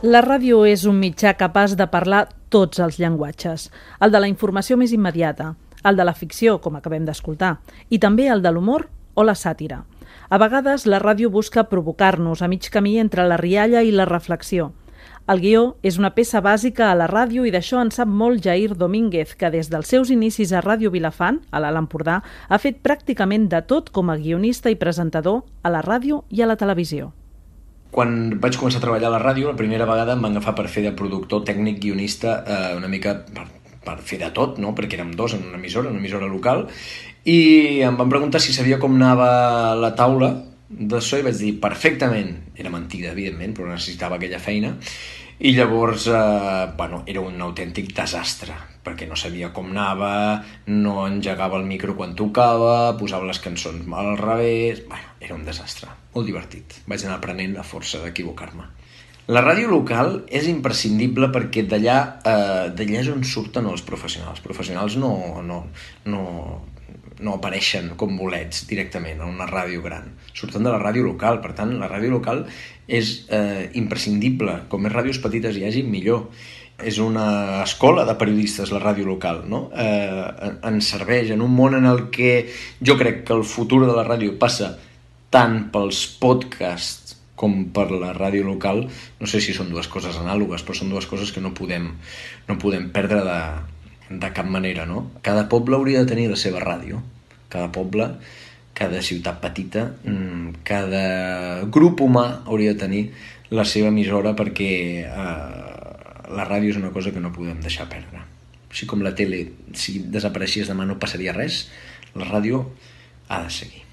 Especial emès amb motiu del Dia Mundial de la Ràdio 2020. Els guionistes a la ràdio amb Jaïr Domínguez.